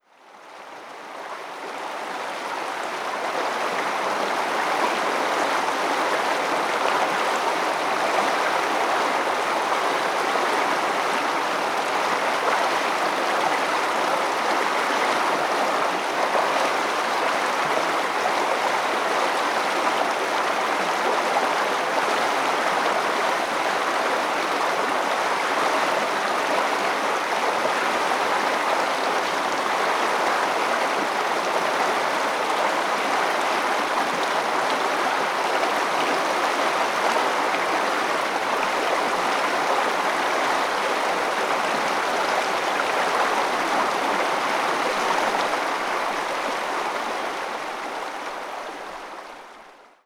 Cottle Creek after three days of heavy rainfall https
Cottle Creek flows into and out of Cottle Lake in the Linley Valley Cottle Lake Park. This recording was captured on a section of the creek flowing from the lake to Departure Bay on November 16, 2021. This marks my first day of field recording and is my first edited soundscape.
TEST-water.wav